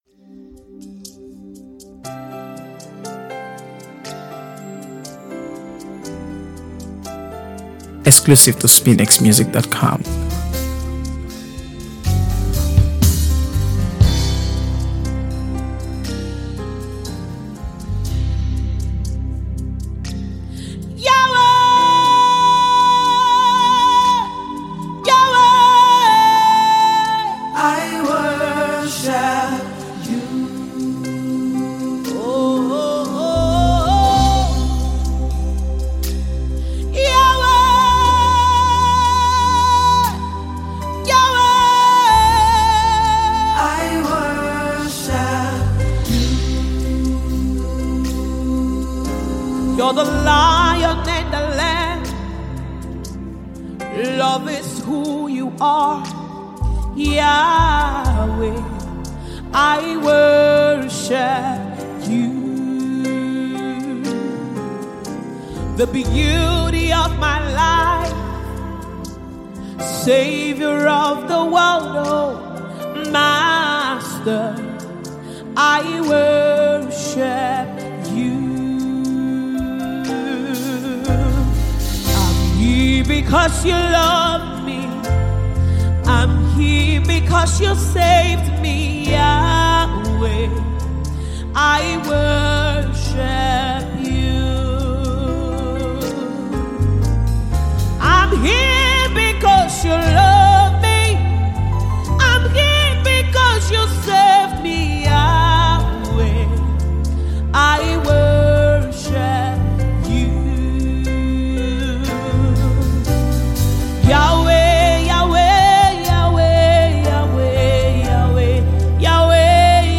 gospel
praise and worship song